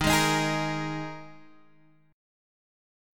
D#sus4 chord